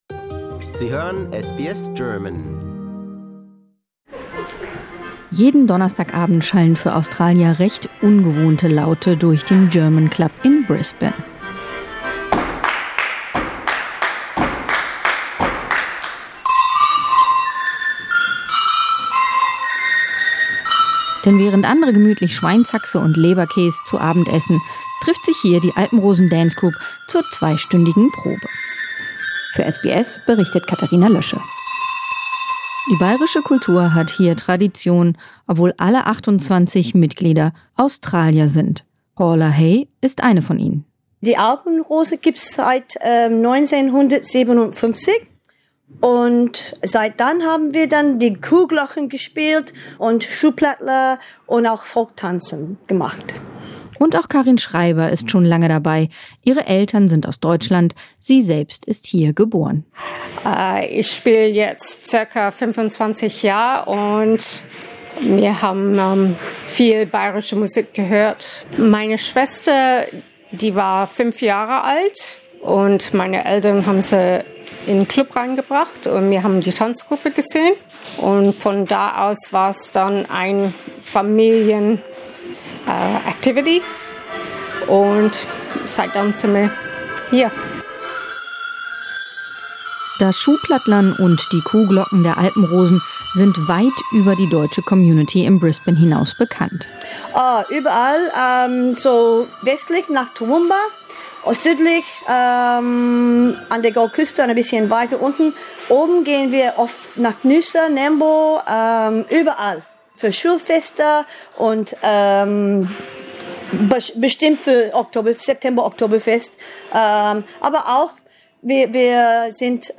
There is a shoeplattler, some cheering and the sound of cow bells.